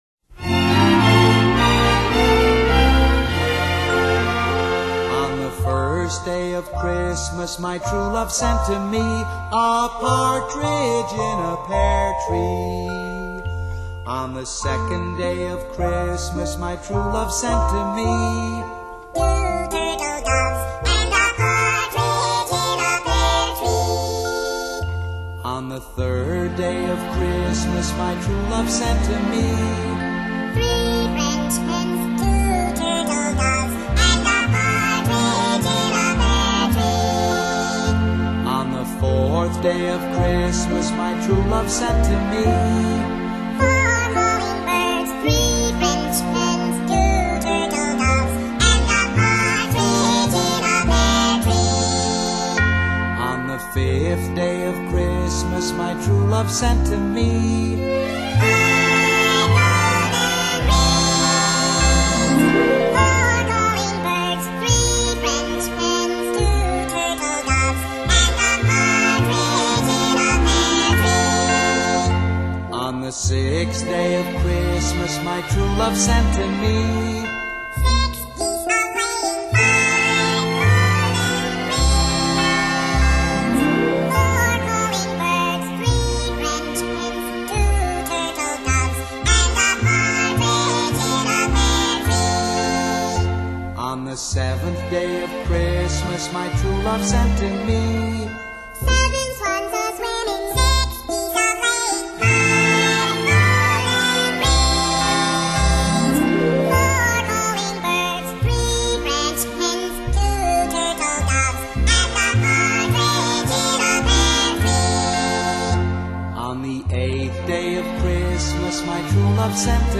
Genre: Christmas